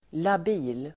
Ladda ner uttalet
Uttal: [lab'i:l]